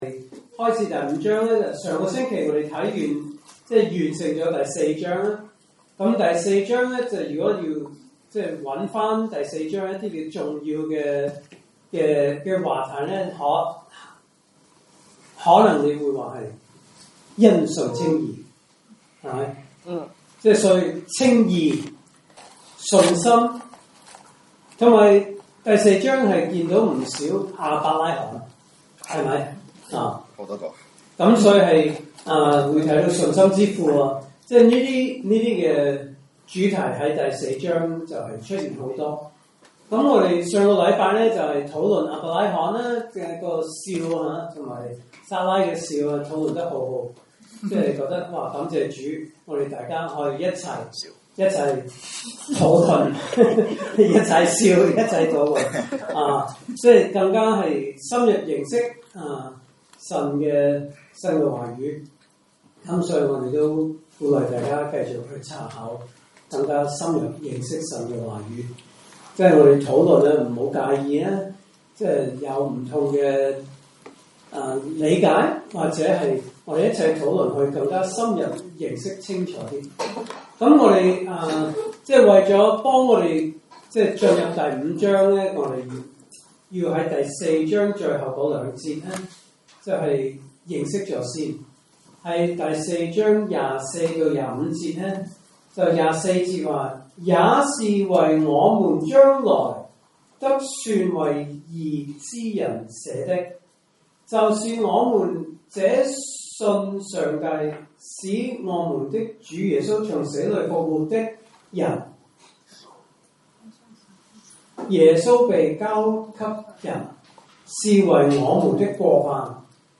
來自講道系列 "查經班：羅馬書"